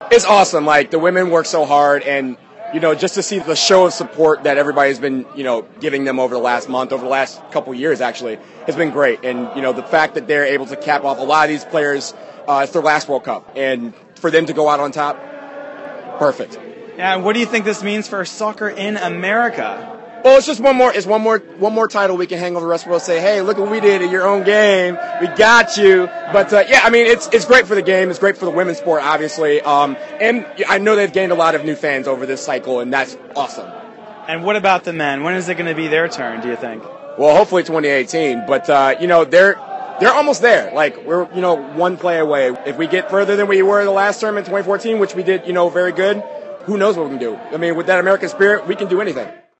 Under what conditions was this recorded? Speaking to VOA at the site